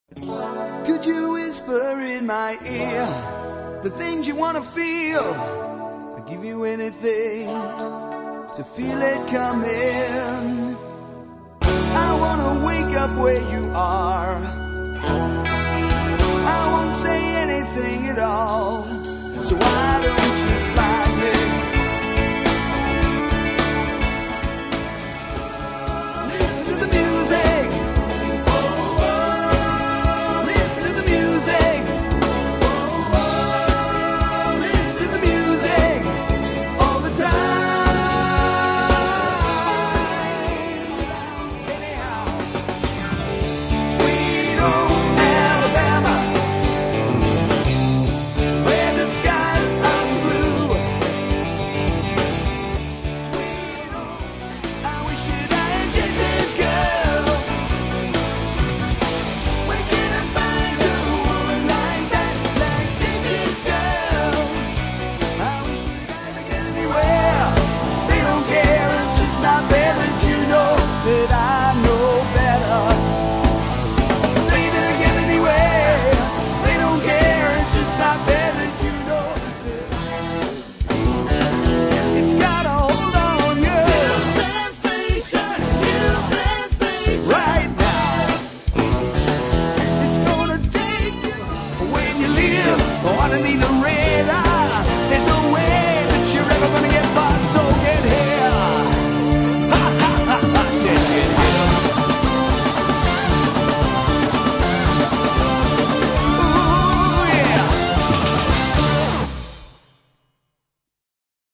LEAD VOCALS.